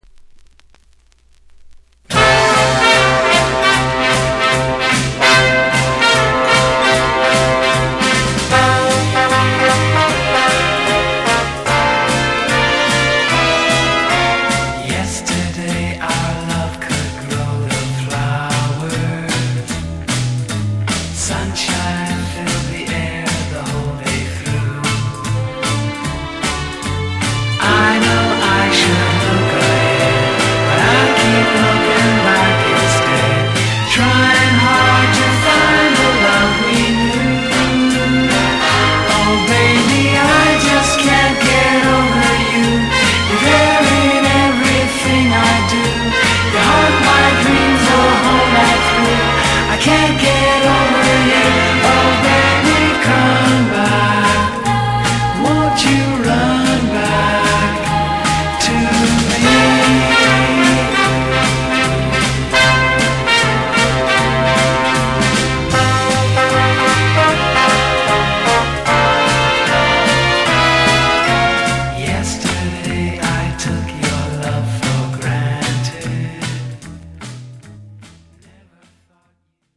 埋もれていてはいけない位にキャッチー、シャッフル調のソフトな♂リードボーカルのソフロ盤。